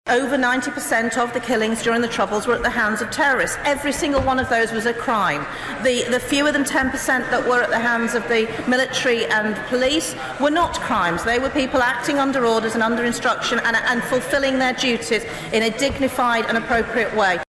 Mrs Bradley made the statement in Westminster this morning…………